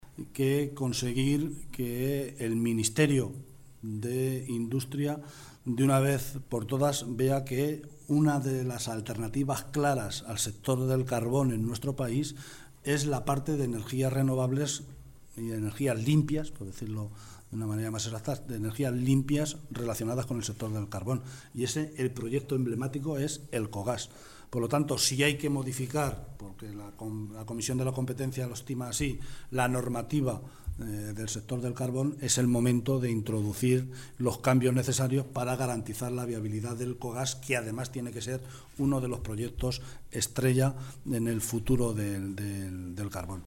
Martínez Guijarro ha efectuado estas declaraciones en la comparecencia conjunta con el presidente de la Diputación de Ciudad Real, José Manuel Caballero, con quien se ha reunido para abordar la situación de la provincia así como las líneas de trabajo a desarrollar por ambas administraciones.